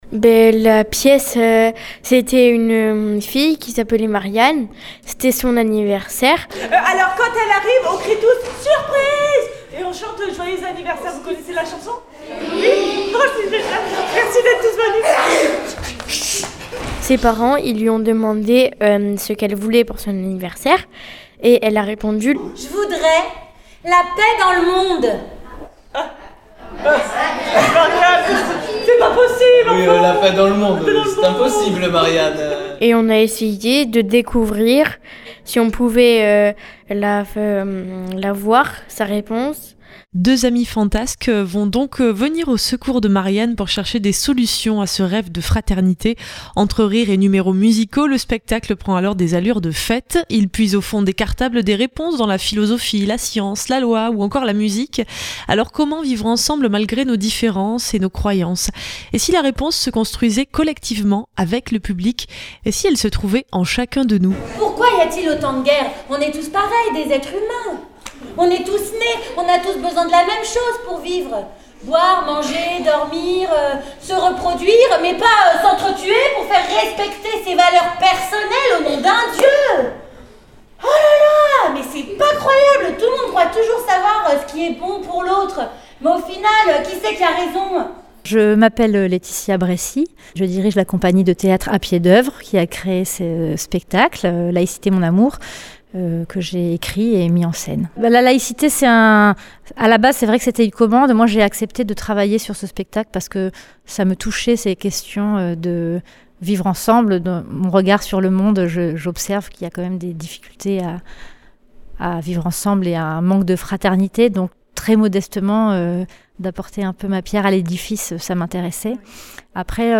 Radio Delta fm Emission enregistrée lors d’une représentation à Aigues-mortes le 17 décembre 2024
reportage-Laicite-mon-amour-college-am-dec-24-reportage-delta-fm.mp3